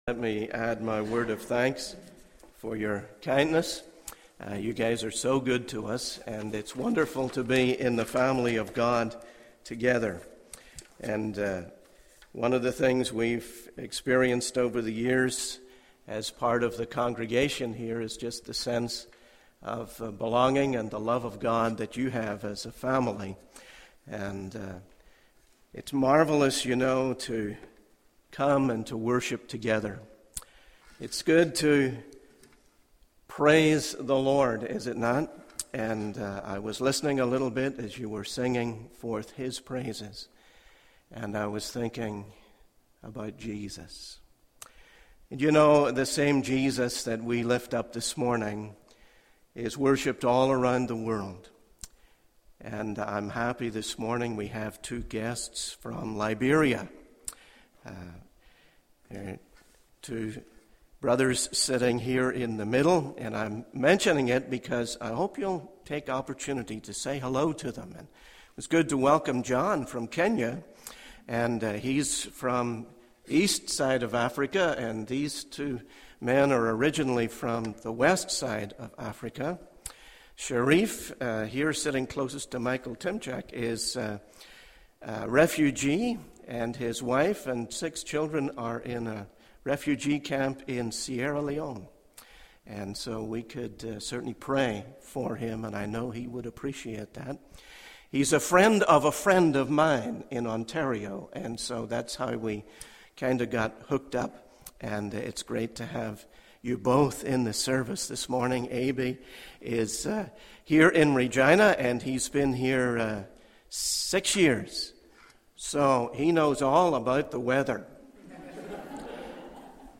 In this sermon, the speaker focuses on the importance of guarding the heart as we enter into a new year. He emphasizes the need to be cautious not to forget the Lord in times of prosperity and not to question God when faced with adversity.